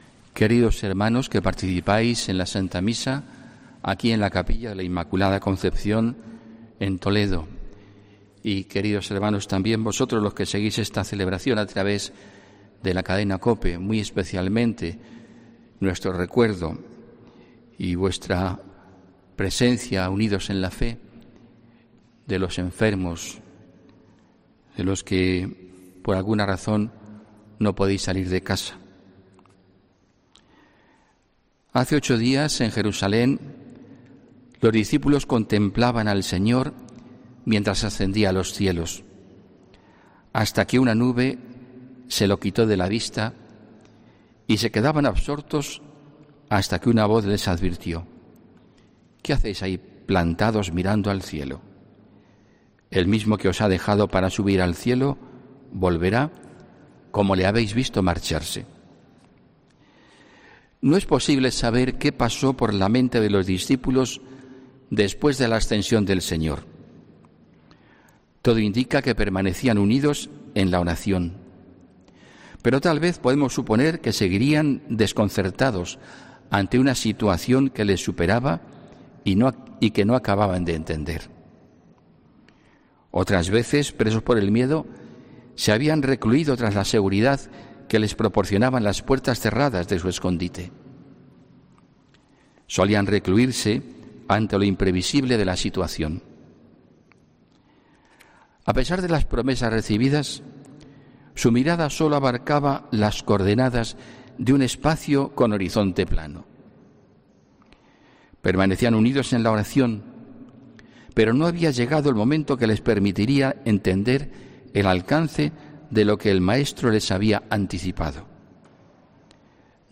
HOMILÍA 31 MAYO 2020